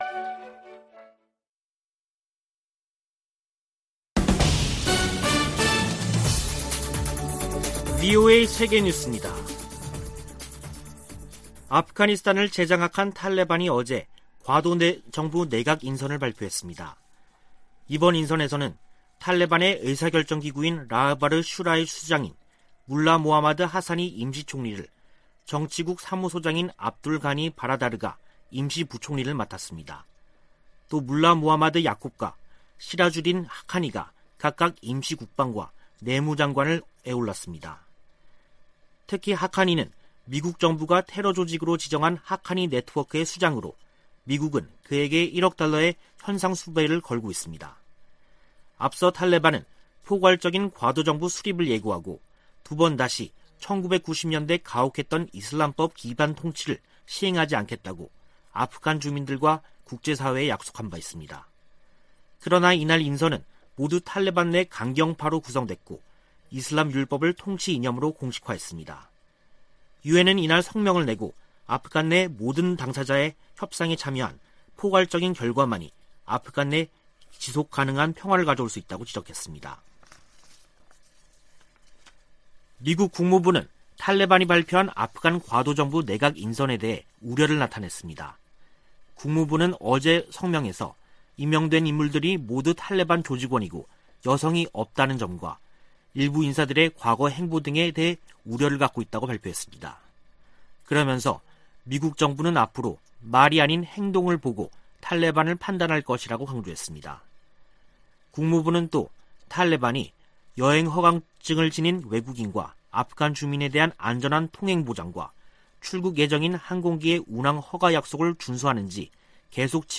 VOA 한국어 간판 뉴스 프로그램 '뉴스 투데이', 2021년 9월 8일 3부 방송입니다. 북한이 9일 열병식을 개최한다면 신형 무기 등장 여부가 최우선 관심사 가운데 하나라고 미국의 전문가들은 밝혔습니다. 미국인 10명 중 5명은 북한의 핵 프로그램에 대해 크게 우려하는 것으로 나타났습니다. 중국과 러시아가 유엔 안전보장이사회에서 대북 제재 완화 분위기를 띄우고 있는 가운데 미국은 제재를 계속 이행할 것이라는 입장을 밝혔습니다.